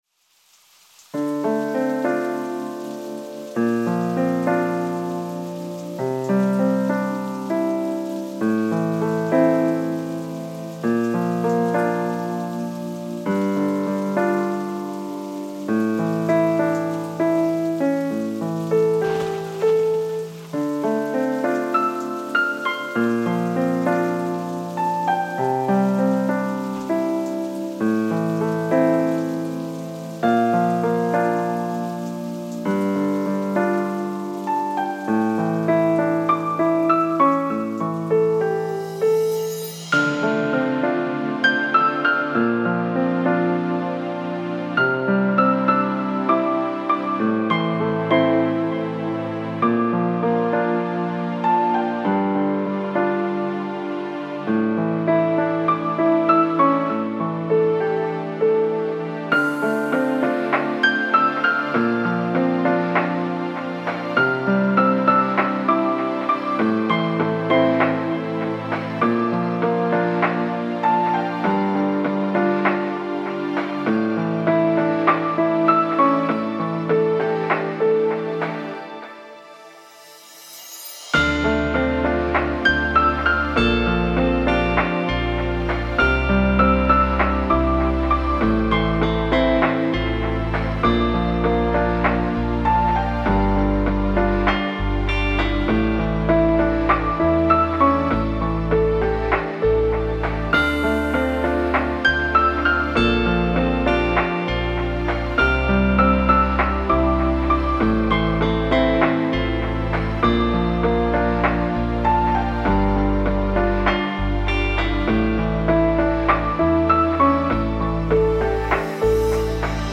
Nev_domij vikonavec' - Sumne .mp3